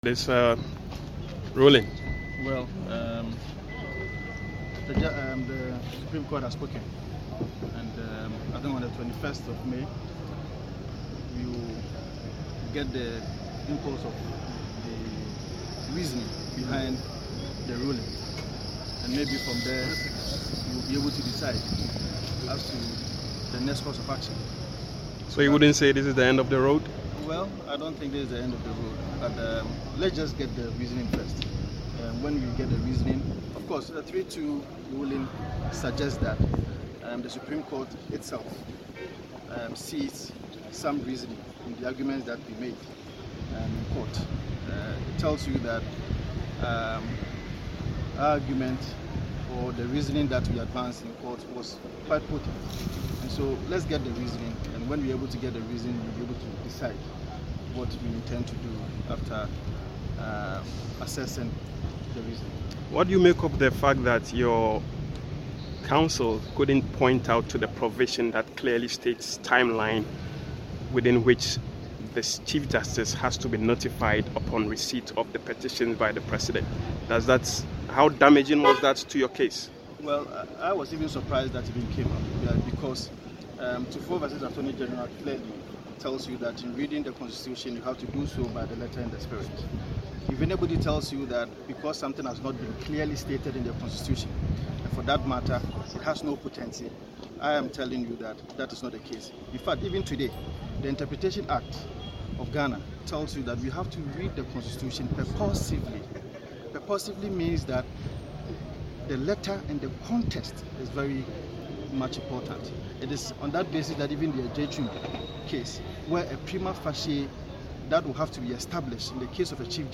Speaking in an interview with JoyNews shortly after the ruling, Mr. Assafuah, who is also the applicant in the injunction application in the CJ’s suspension case, said the Supreme Court’s ruling is not necessarily the end of the legal road.